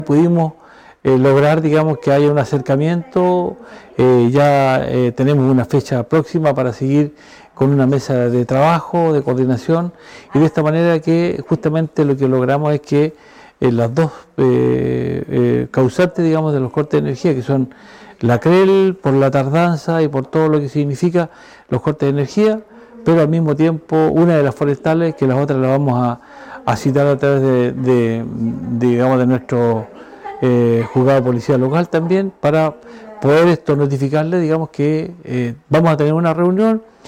SEC participa en Mesa de Trabajo para abordar recientes interrupciones eléctricas en sectores rurales de la comuna de Fresia En la ocasión, el Alcalde de Fresia, Miguel Cárdenas, valoró la reunión, para buscar soluciones en conjunto.